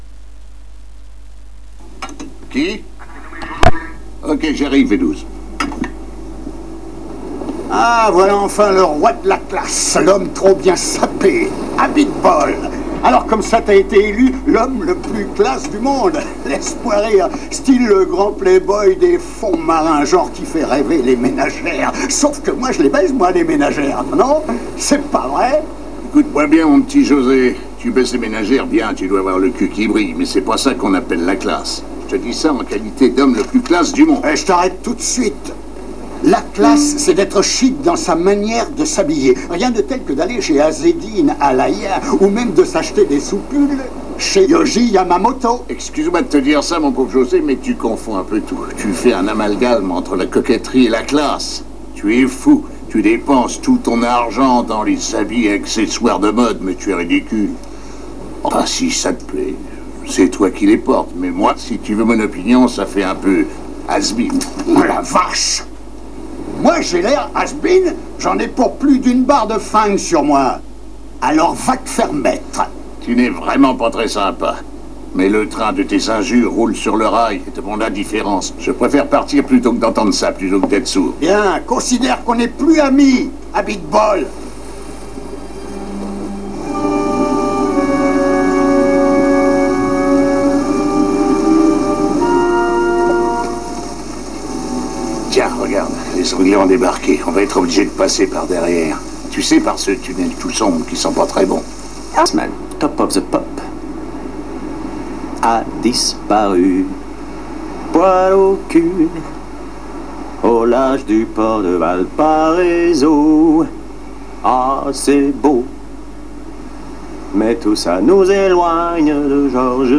je me suis fait des petites compiles avec des extraits du films si vous voulez.....